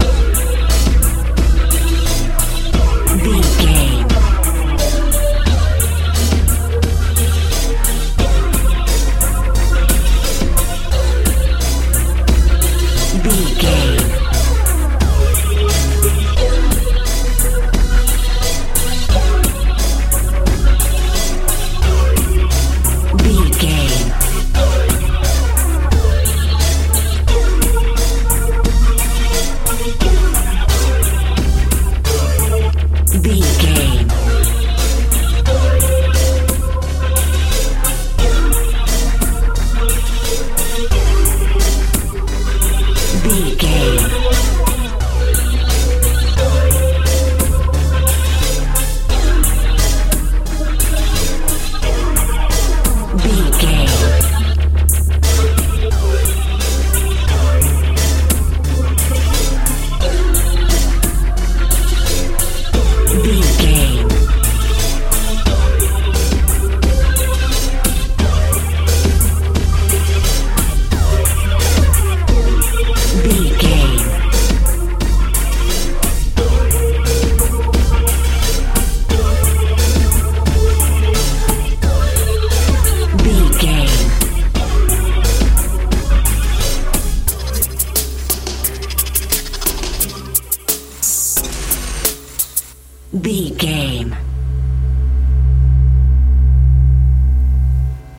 modern dance
Locrian
B♭
tension
haunting
industrial
ominous
dark
synthesiser
drum machine